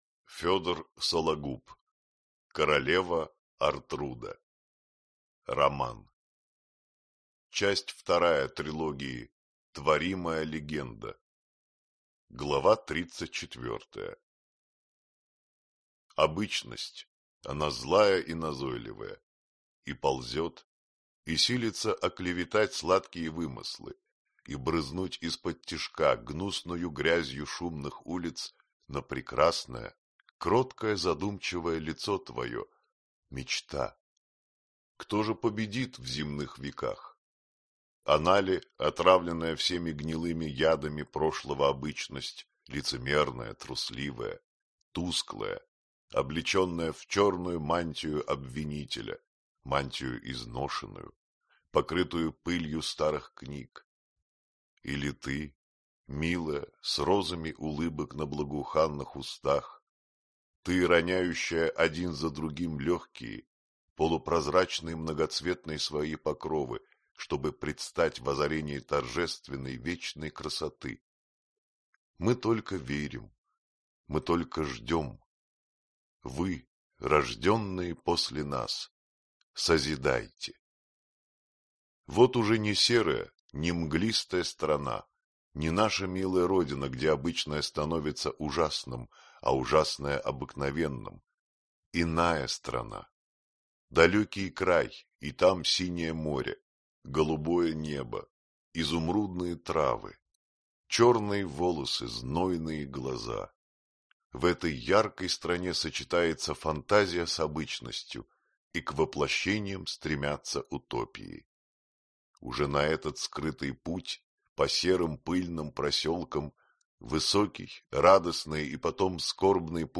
Аудиокнига Королева Ортруда | Библиотека аудиокниг